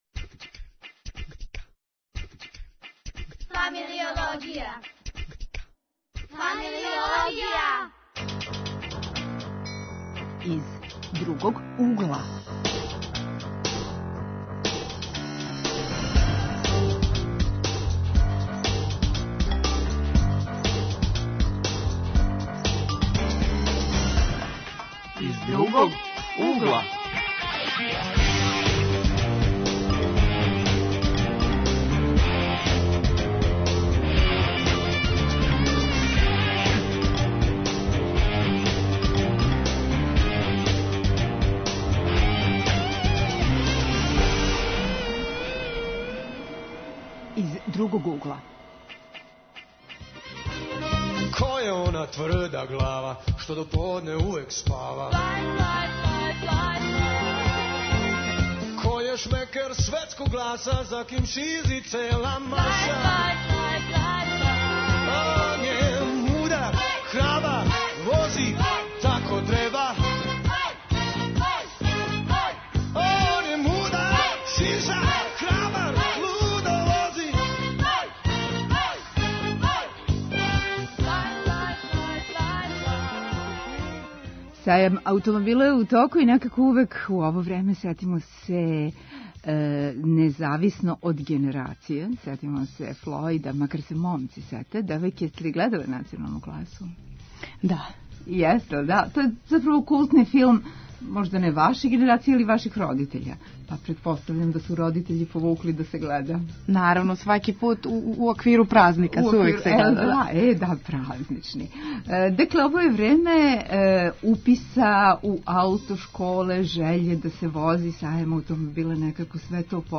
Гости су нам студенти, вршњачки едукатори Центра за позитиван развој деце и омладине, чија је тема "или возиш или пијеш".